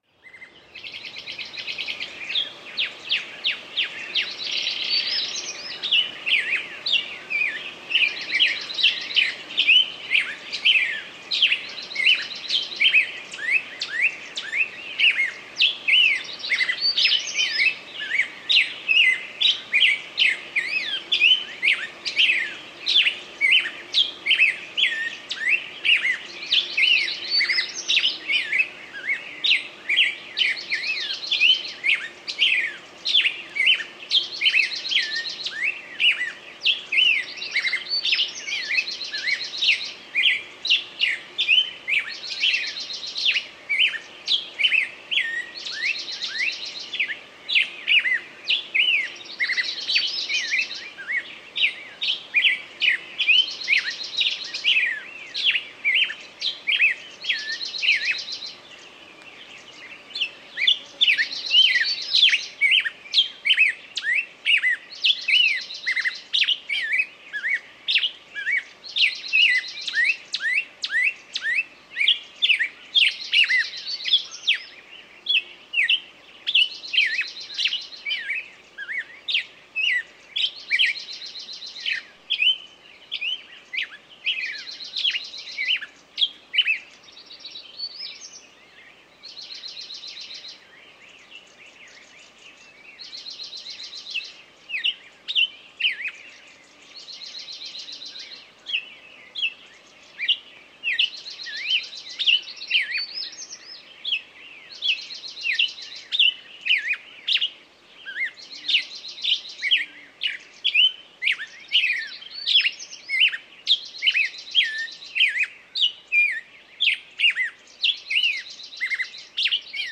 Пение птиц